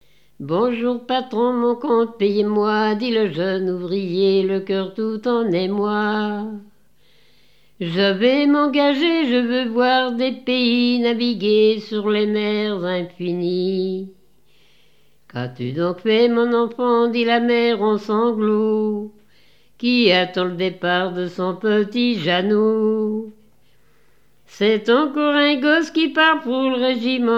Genre strophique
Interprétation de chansons à partir d'un cahier de chansons
Pièce musicale inédite